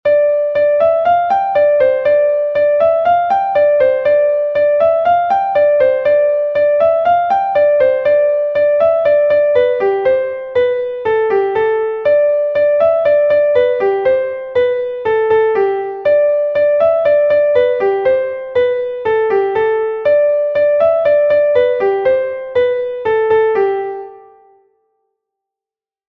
Laridé Koun I est un Laridé de Bretagne enregistré 1 fois par Koun